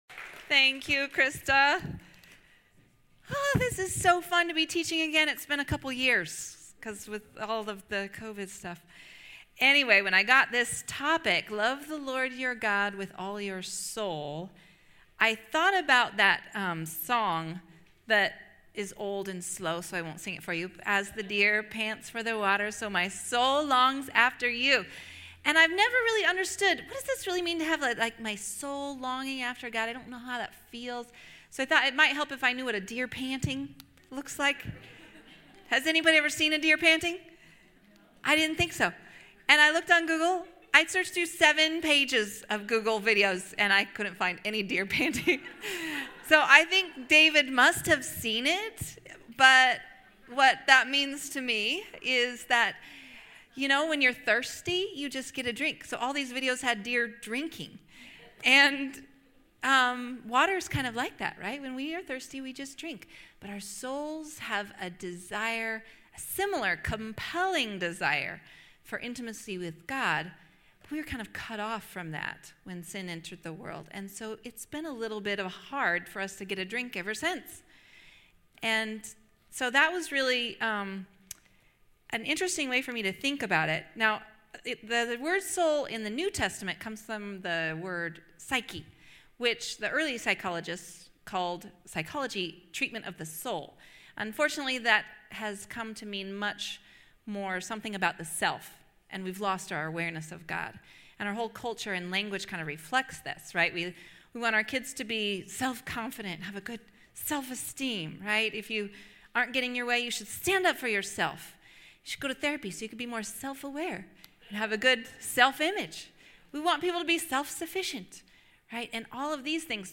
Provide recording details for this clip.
In this message, we’ll discover what the Bible says about it and how to remove the traps that keep us from living it out. Ladies Night Loving God With All Your Soul